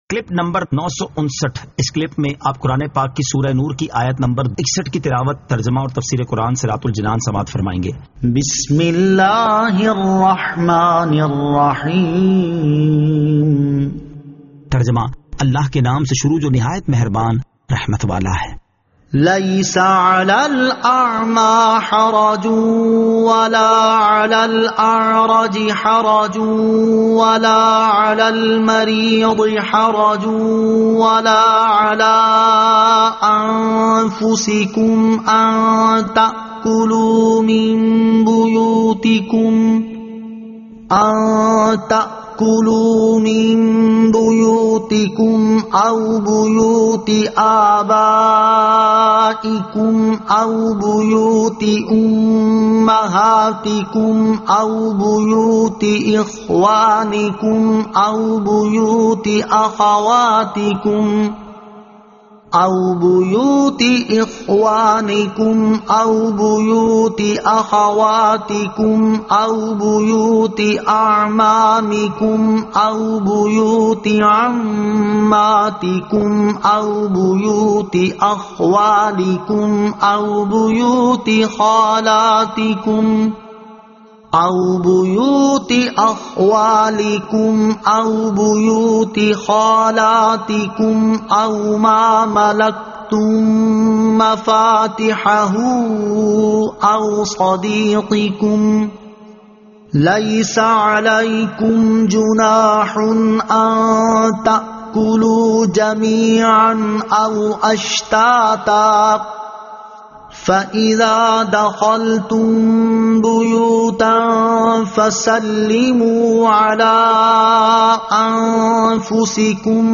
Surah An-Nur 61 To 61 Tilawat , Tarjama , Tafseer